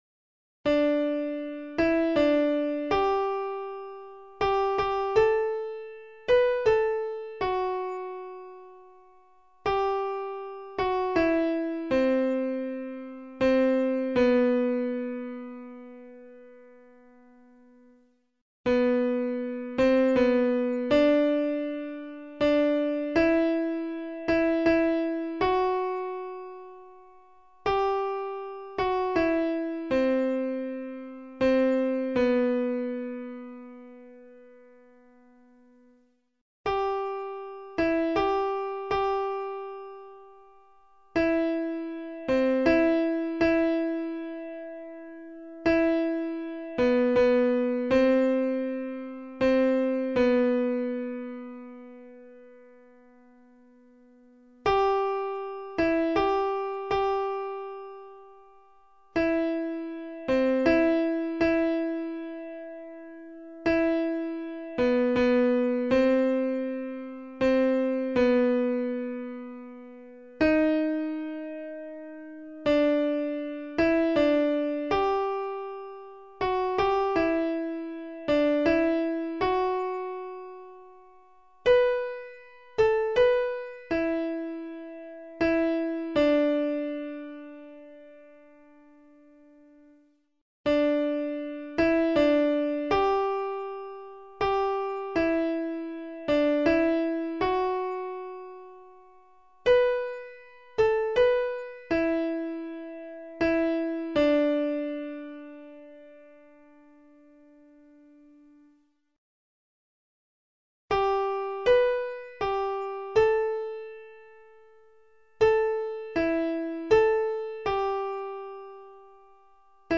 Information and practice vocal scores & mp3's for the